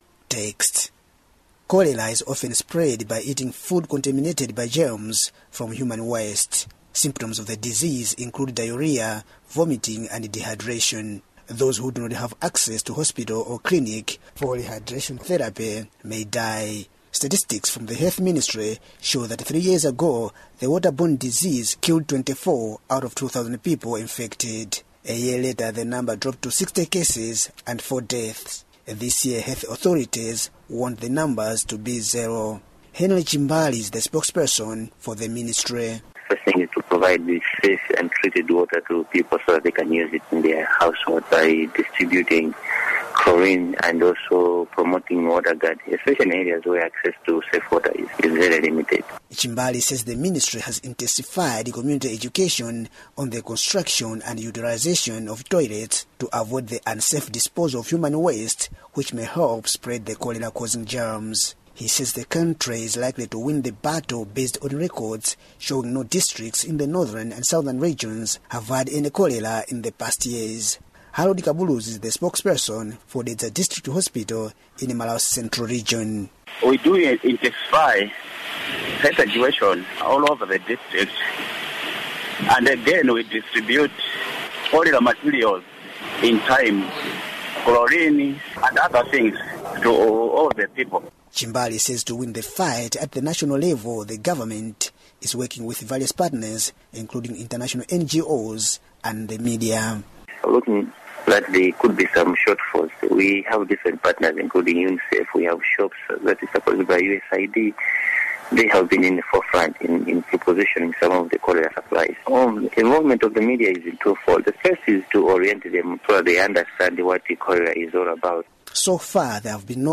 Listen to report on anti-cholera efforts in Malawi